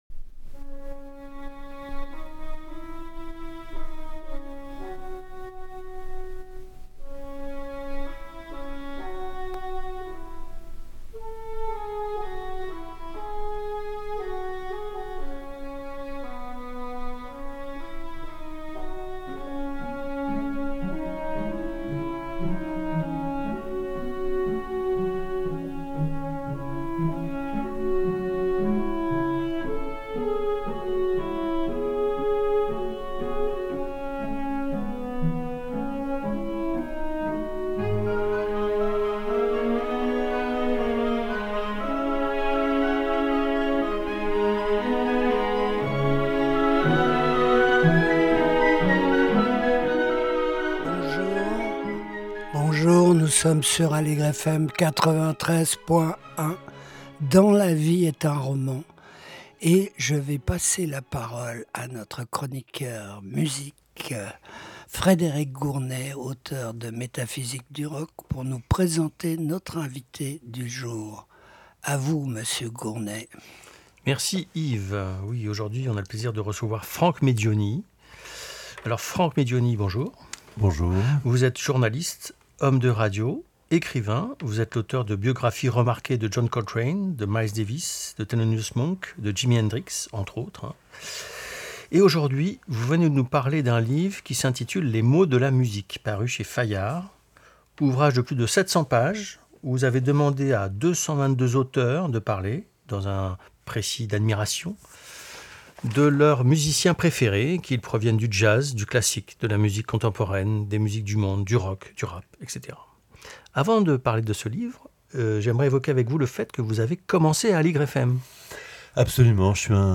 Il est interviewé